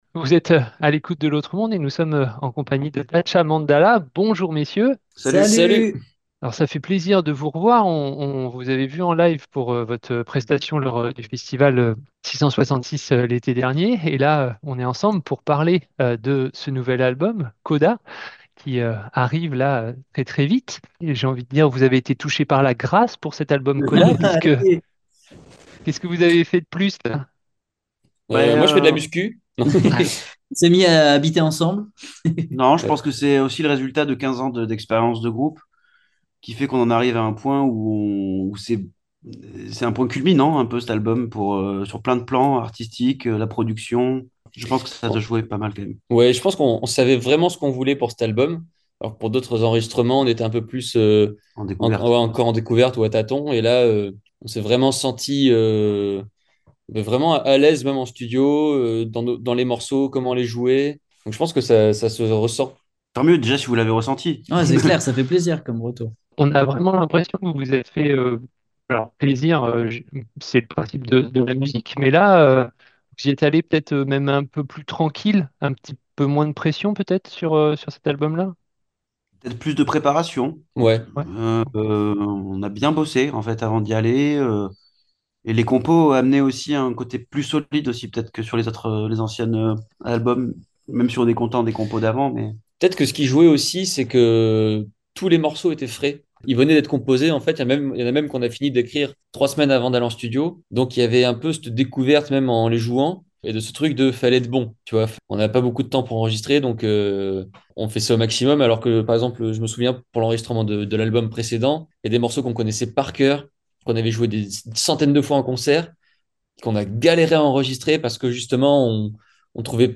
interview enregistrée le 27.03.2024 du groupe DATCHA MANDALA :